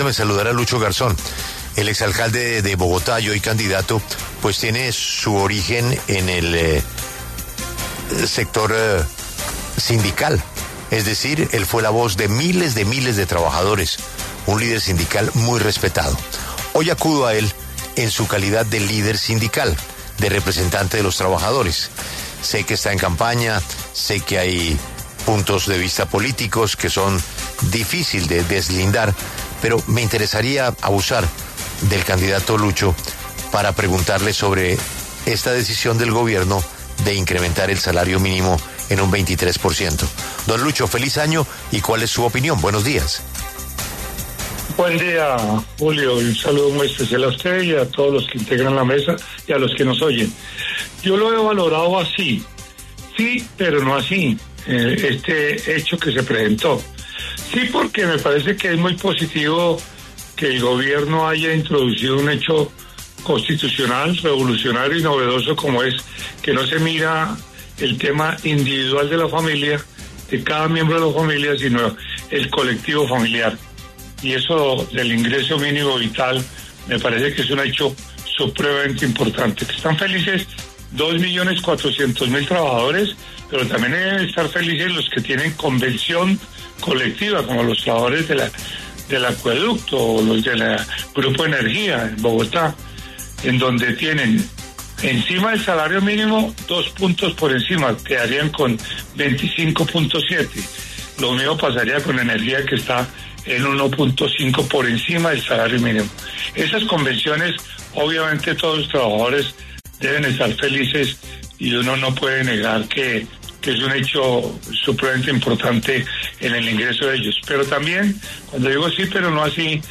Luis Eduardo “Lucho” Garzón, exalcalde de Bogotá y candidato al Senado, pasó por los micrófonos de La W para hablar sobre el incremento del salario mínimo para 2026.